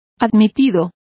Complete with pronunciation of the translation of accepted.